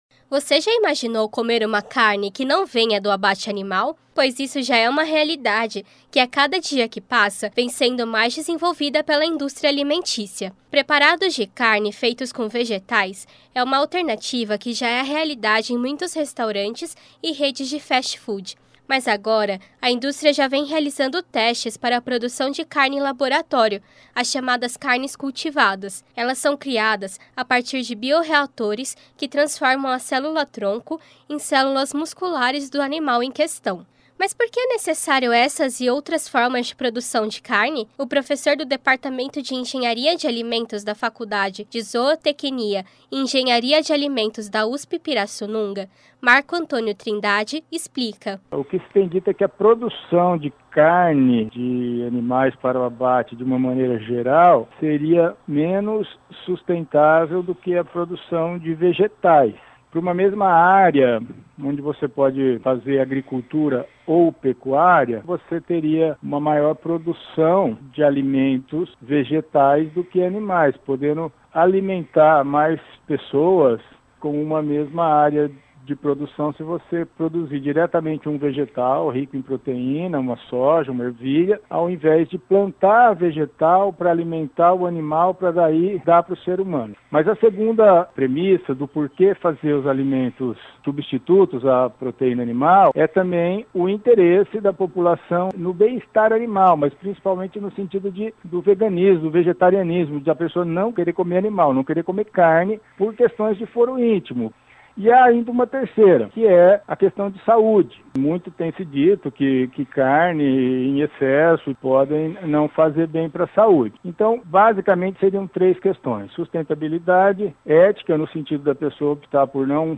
Ouça a reportagem completa no player acima